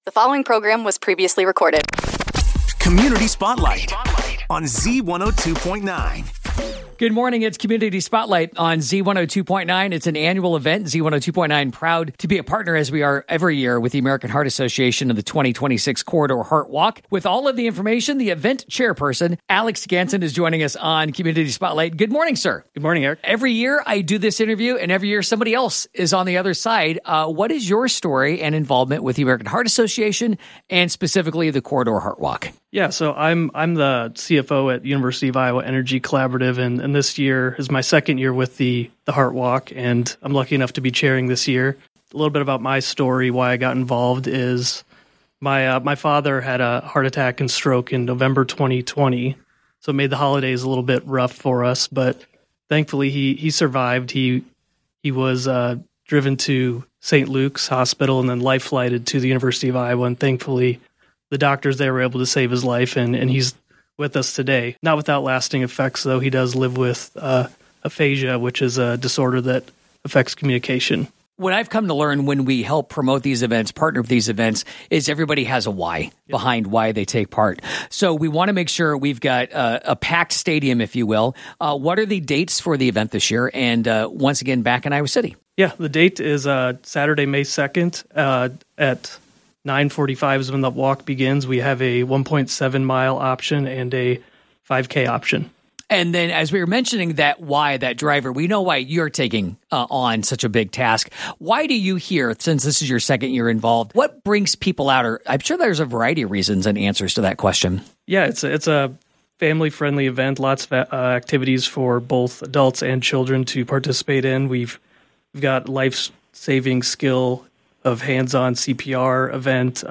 Guest